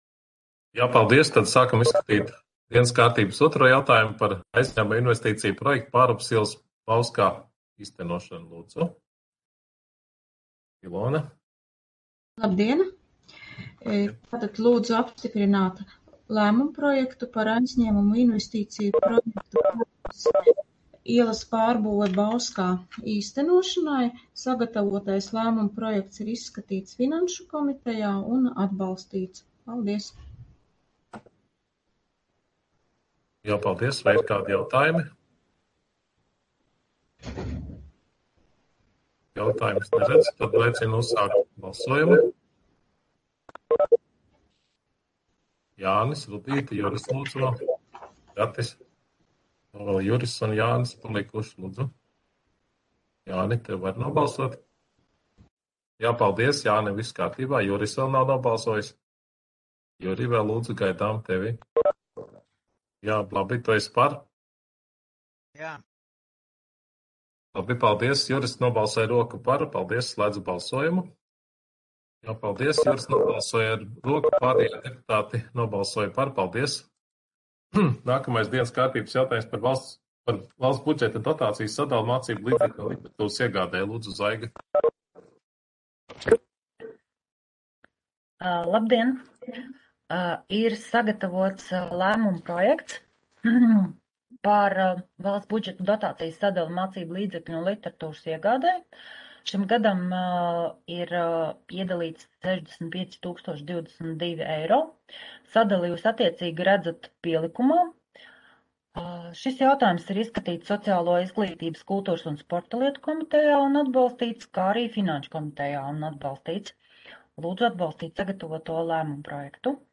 Audioieraksts - 2021.gada 27.maija domes sēde